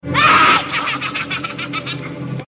witch-laughing_14200.mp3